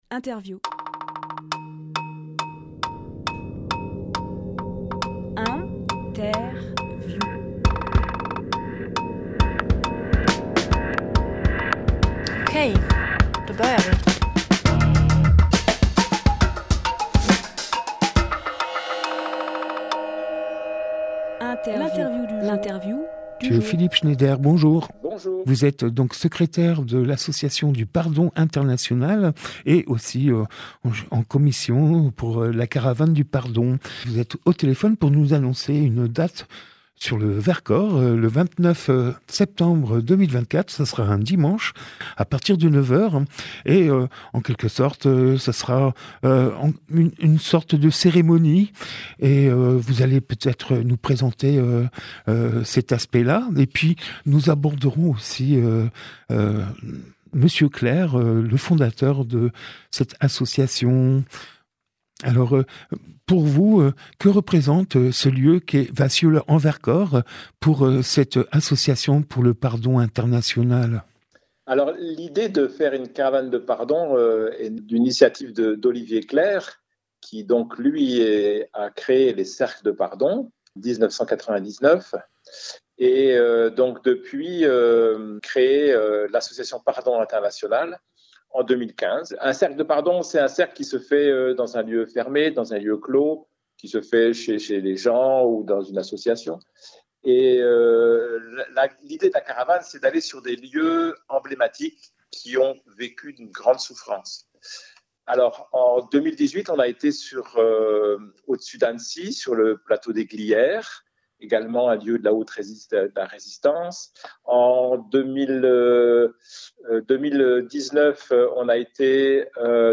Emission - Interview Association Internationale du Pardon – Le Grand Ecart- Roda Viva Samba Publié le 26 septembre 2024 Partager sur…
Lieu : Studio Rdwa